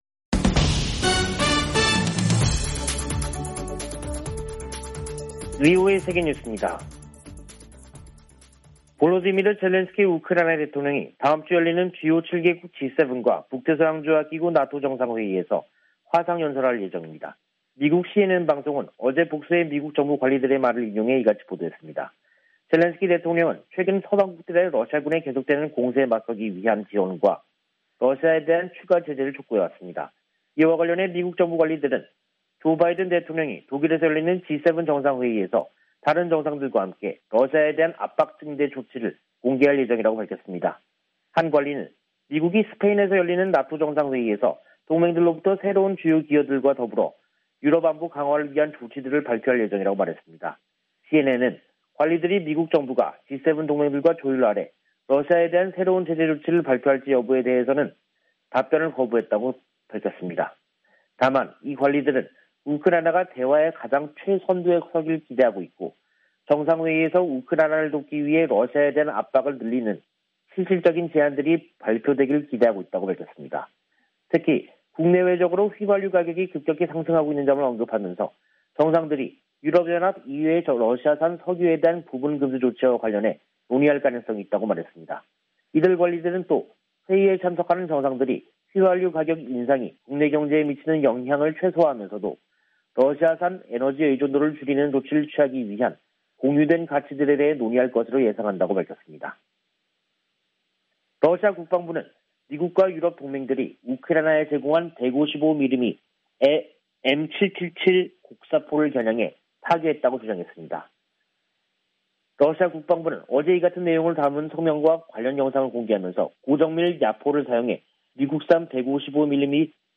세계 뉴스와 함께 미국의 모든 것을 소개하는 '생방송 여기는 워싱턴입니다', 2022년 6월 23일 저녁 방송입니다. '지구촌 오늘'에서는 우크라이나의 유럽연합(EU) 후보국 지위 확정 가능성이 높다는 이야기, '아메리카 나우'에서는 조 바이든 대통령이 유류세 일시 면제를 의회에 요청한 소식 전해드립니다.